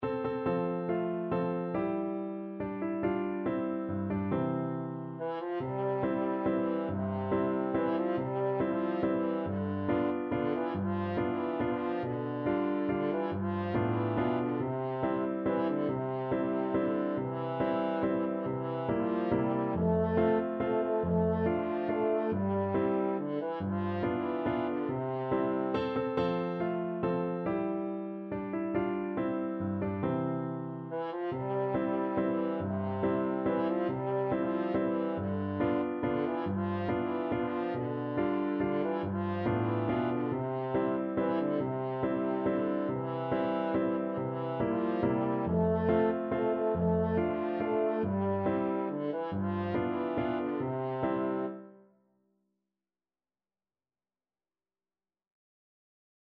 French Horn
Traditional Music of unknown author.
C major (Sounding Pitch) G major (French Horn in F) (View more C major Music for French Horn )
~ = 100 Simply =c.140
3/4 (View more 3/4 Music)